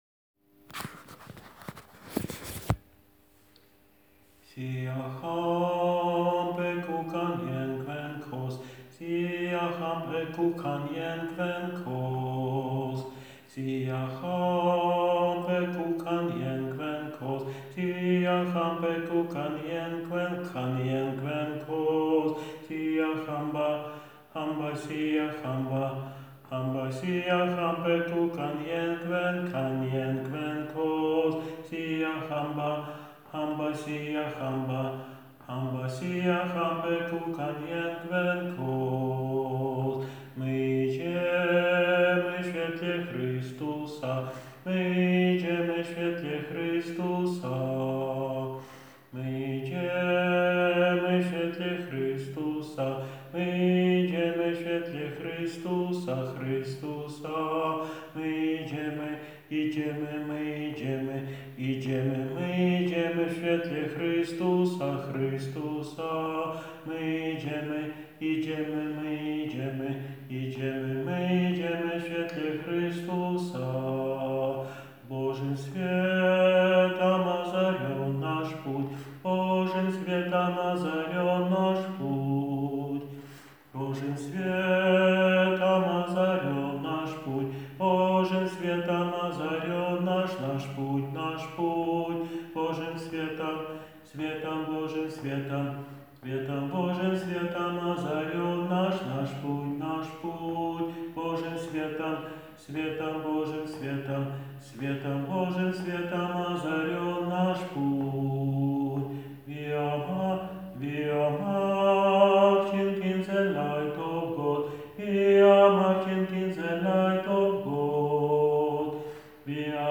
Siyahamba Sopran 2 - nagranie utworu z głosem nauczyciela ze słowami a capella (bez pomocy instrumentu)